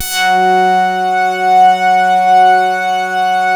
BAND PASS .5.wav